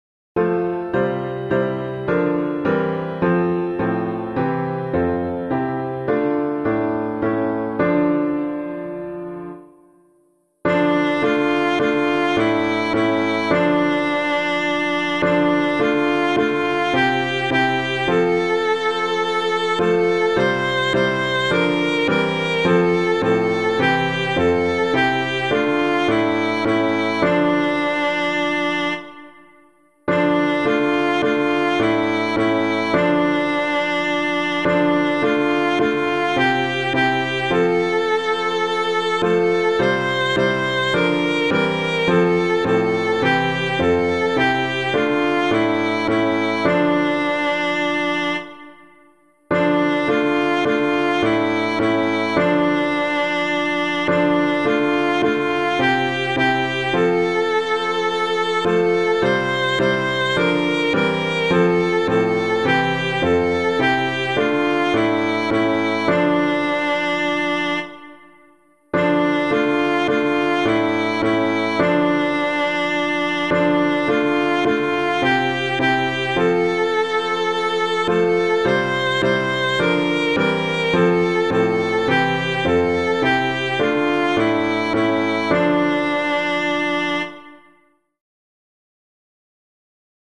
Hymn suitable for Catholic liturgy
Your Ways Are Not Our Own [Bayler - SOUTHWELL] - piano.mp3